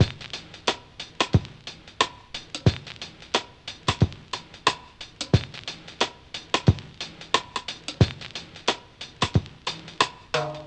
RAGGAVINYL-L.wav